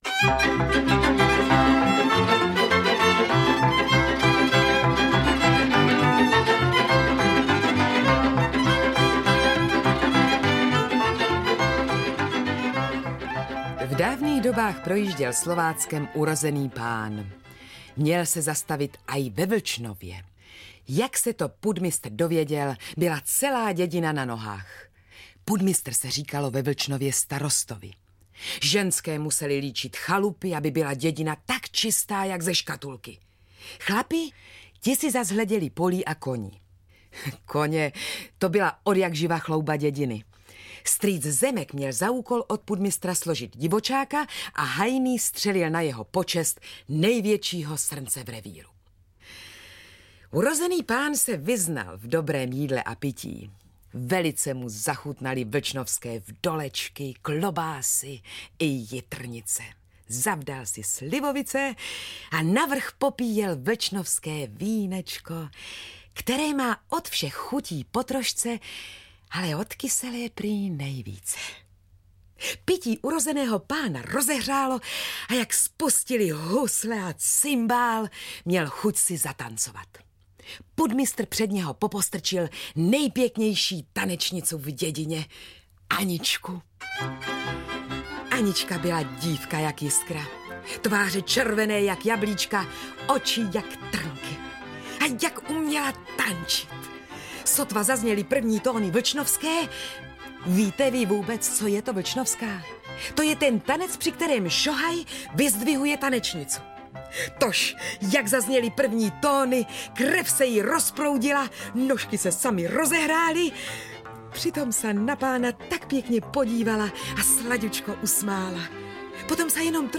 Ukázka z knihy
Pověsti jsou vyprávěny jazykem jadrným, neboť tu více než kde jinde platí, že "kopaničáři nikdy nešli daleko pro slovo".Jsou vyprávěny různorodým nářečím, co dolina, to jiný dialekt: straňanský, březovský, kopaničářský....Tradiční projev prostých lidí zůstává otevřeným zdrojem inspirace hudby, tance, zpěvu a vyprávění.Starší vypravěči z moravsko-slovenského pomezí pomalu odcházejí a s nimi i vyprávění a pověsti.
• InterpretAlois Švehlík, Jana Andresíková, Ilja Prachař